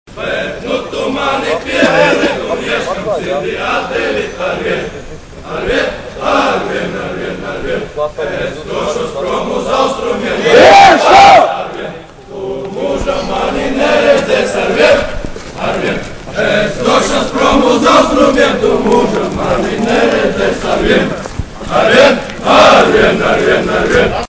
Once the flag was up, we were told to join the unit - positioned into the column on the end, rather than walk to the side.